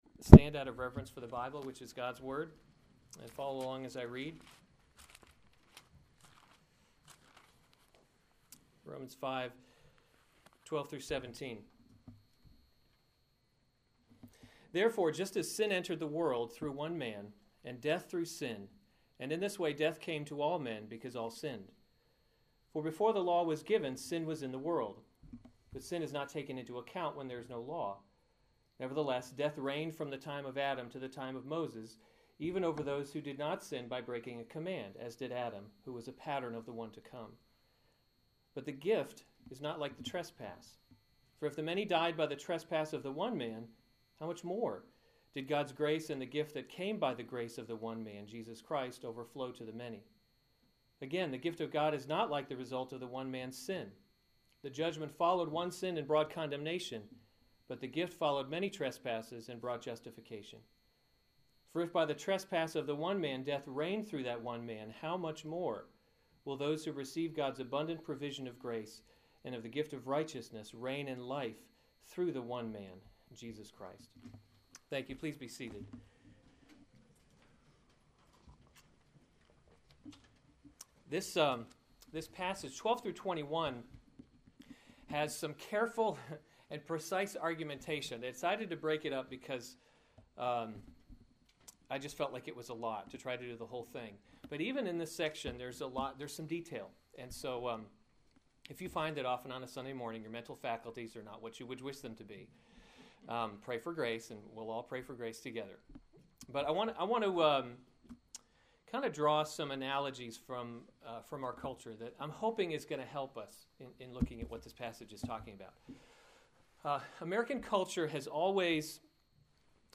April 26, 2014 Romans – God’s Glory in Salvation series Save/Download this sermon Romans 5:12-17 Other sermons from Romans Death in Adam, Life in Christ 12 Therefore, just as sin came […]